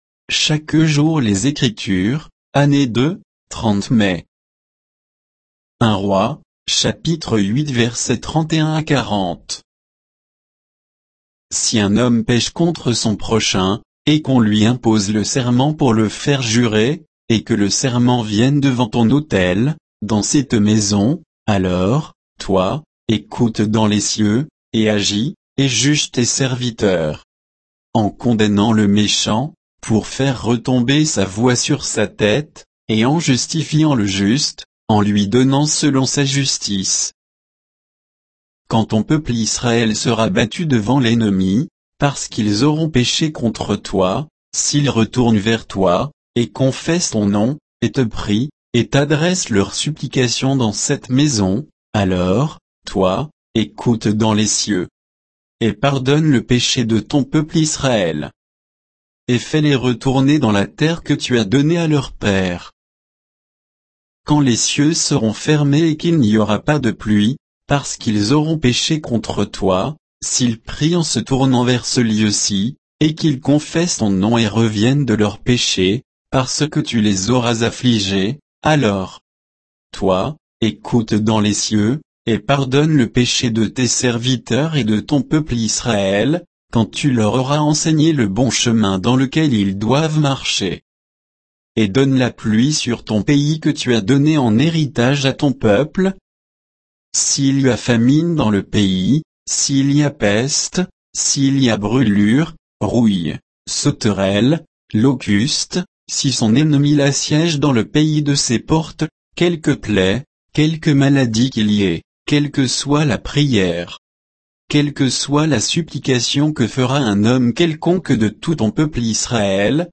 Méditation quoditienne de Chaque jour les Écritures sur 1 Rois 8